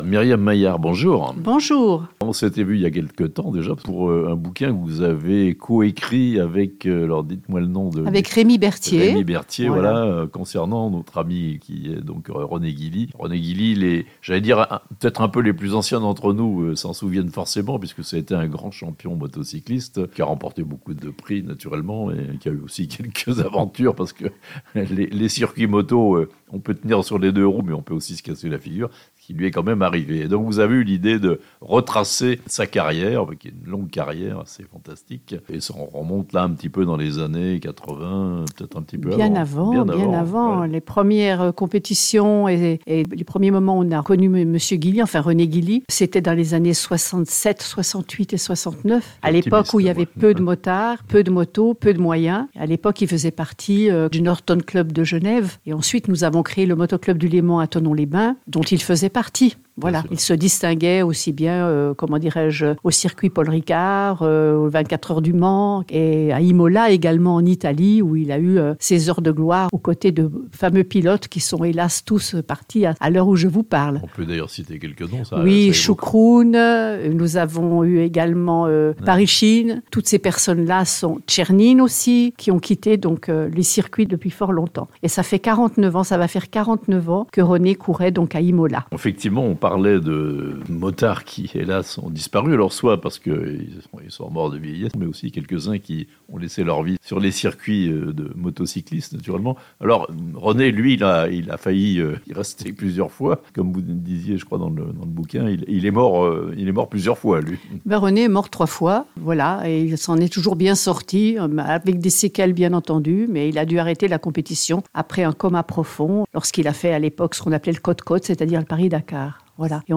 (intervew)